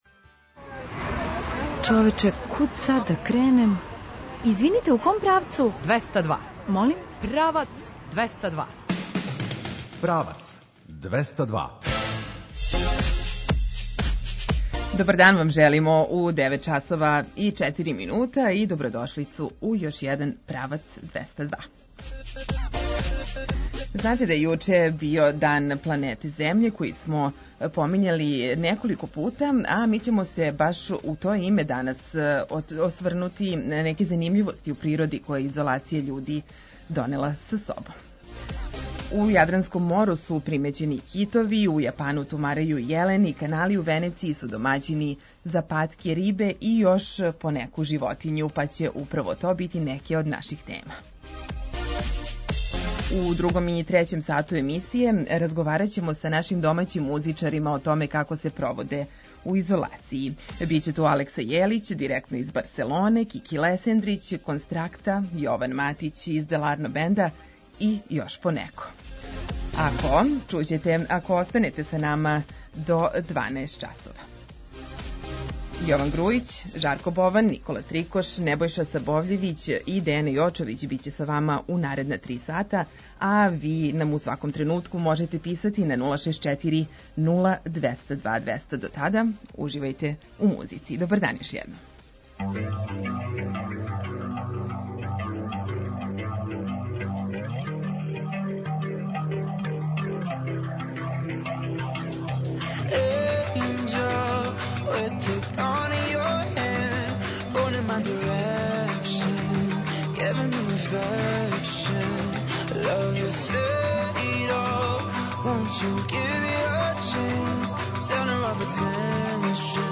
У другом и трећем сату емисије разговарамо са домаћим музичарима како се проводе у изолацији.